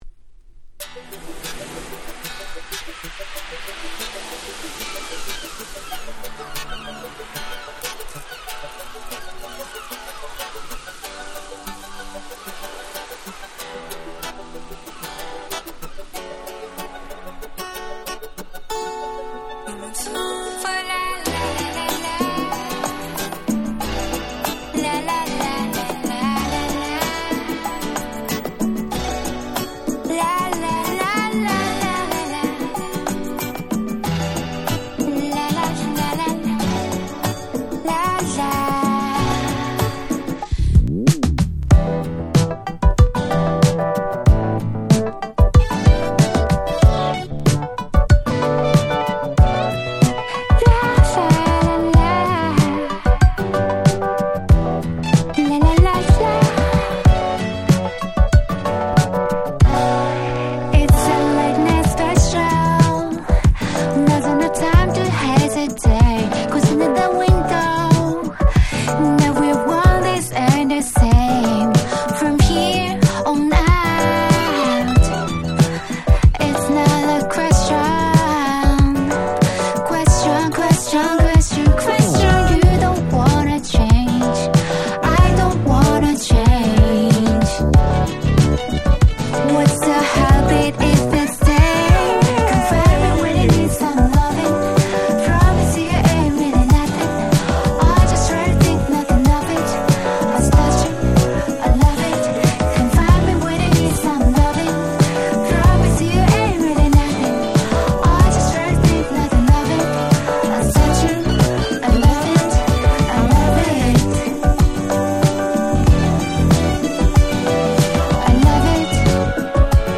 23' Nice City Pop / Japanese R&B !!